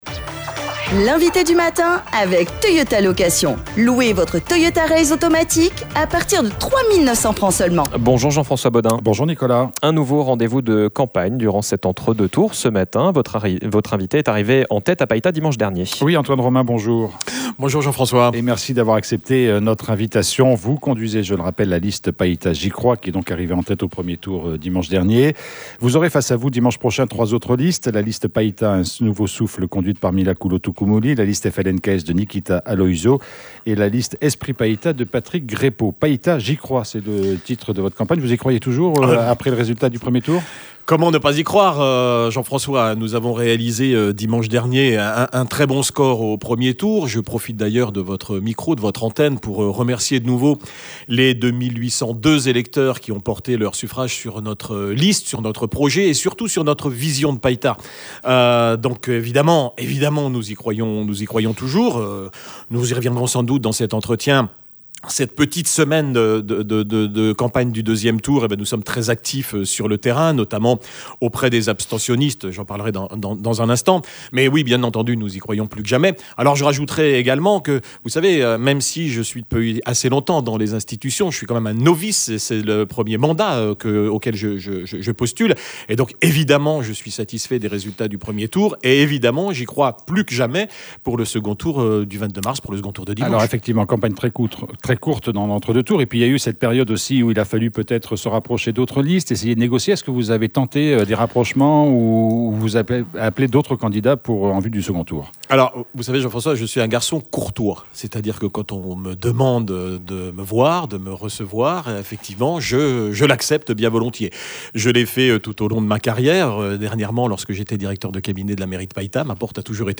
Nous poursuivons nos interviews spéciales municipales sur RRB.